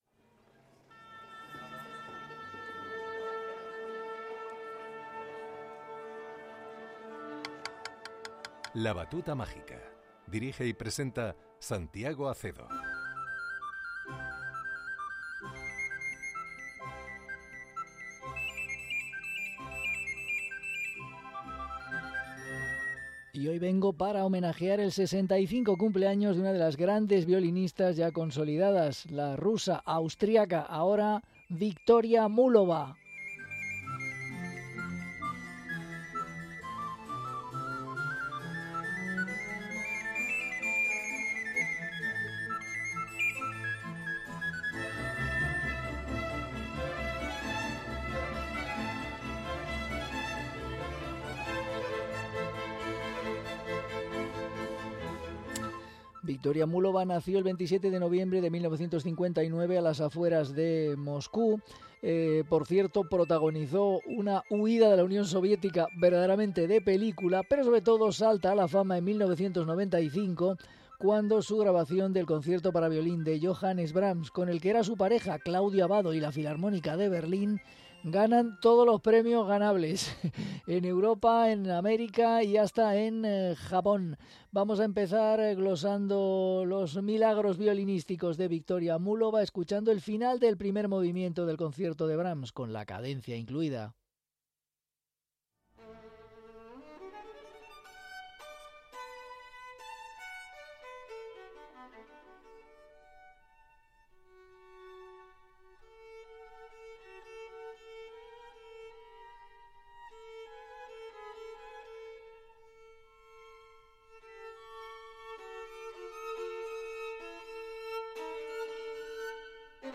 violinista
Concierto para Violín